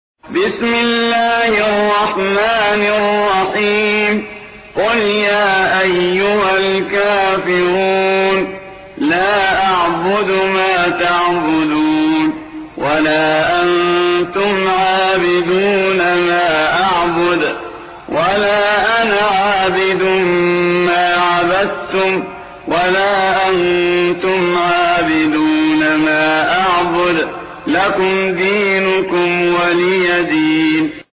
109. سورة الكافرون / القارئ